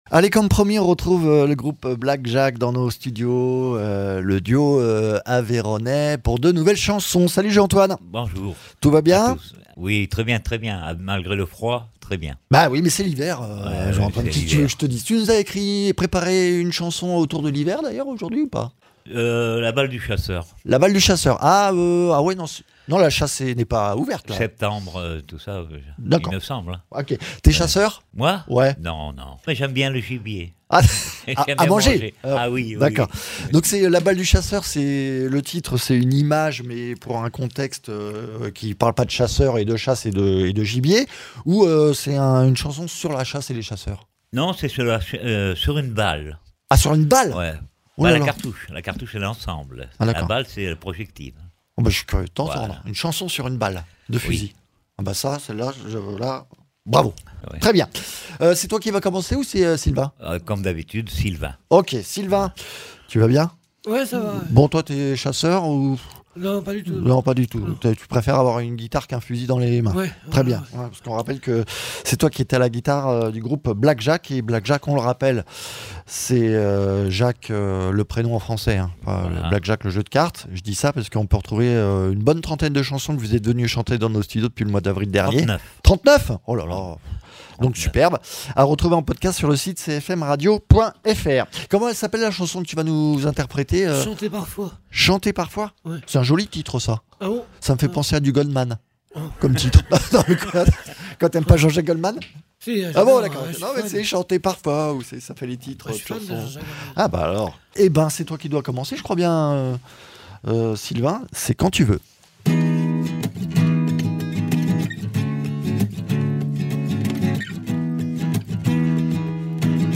en live dans nos studios